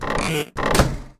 sunos_bau_abrir_fechar.ogg